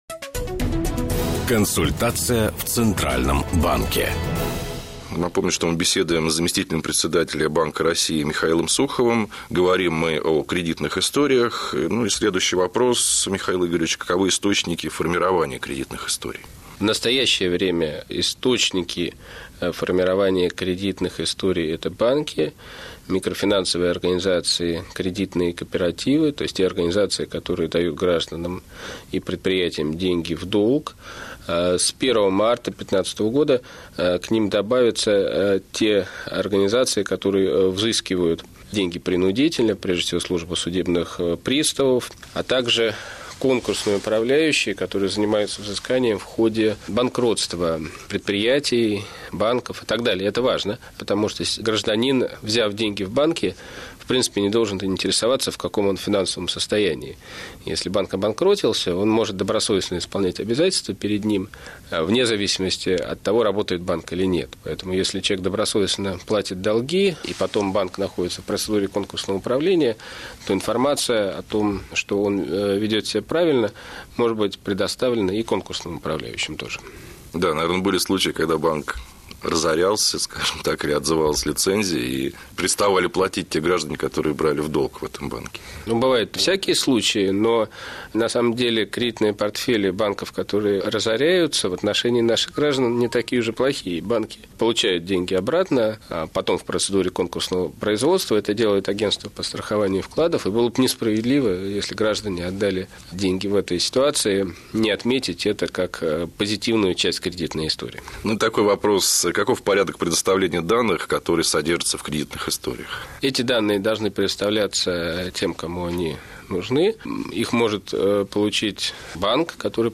Интервью
Интервью заместителя Председателя Банка России М.И. Сухова радиостанции «Бизнес ФМ» 2 октября 2014 года (часть 2)